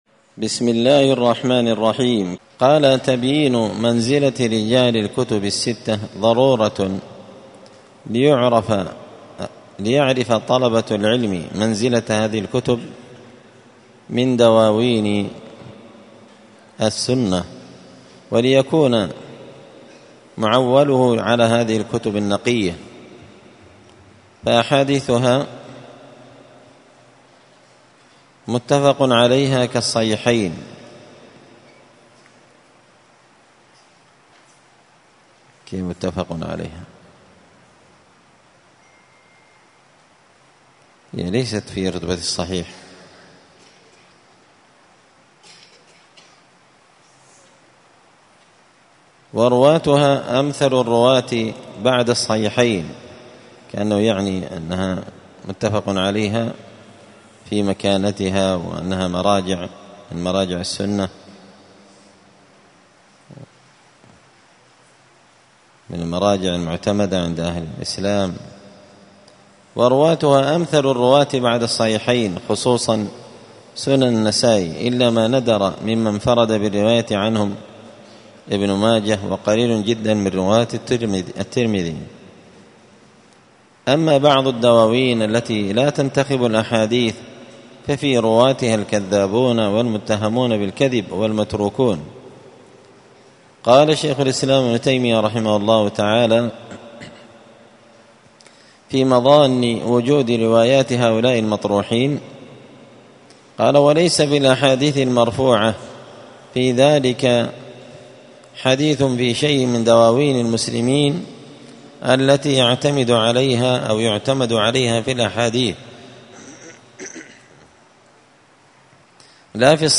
الخميس 9 جمادى الأولى 1445 هــــ | الدروس، المحرر في الجرح والتعديل، دروس الحديث وعلومه | شارك بتعليقك | 83 المشاهدات
مسجد الفرقان قشن_المهرة_اليمن